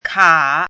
chinese-voice - 汉字语音库
ka3.mp3